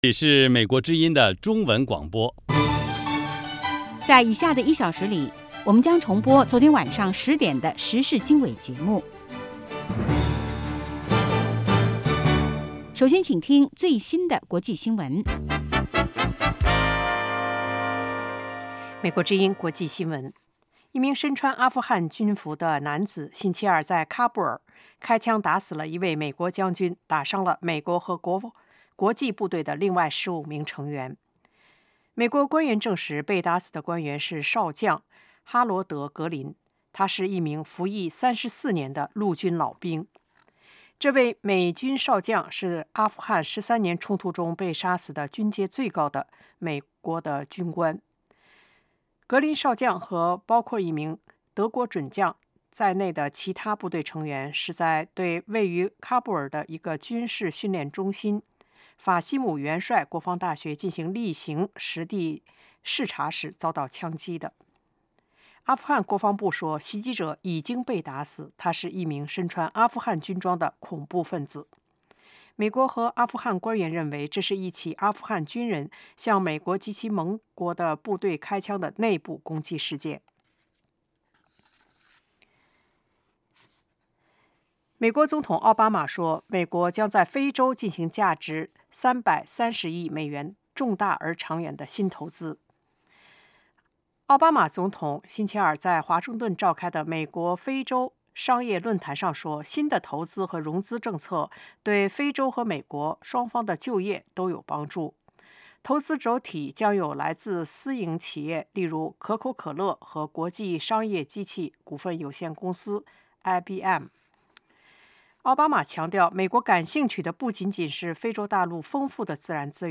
周一至周五：国际新闻 时事经纬(重播) 周六：时事经纬 听众热线 (重播) 北京时间: 上午8点 格林威治标准时间: 0000 节目长度 : 60 收听: mp3